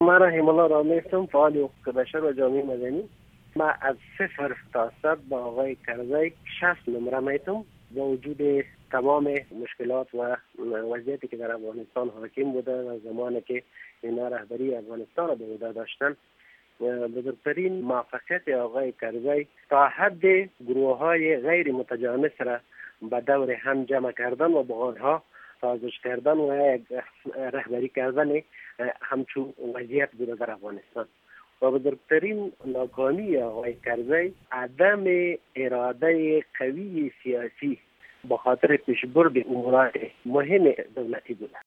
The URL has been copied to your clipboard No media source currently available 0:00 0:00:41 0:00 لینک دانلود | ام‌پی ۳ برای شنیدن مصاحبه در صفحۀ جداگانه اینجا کلیک کنید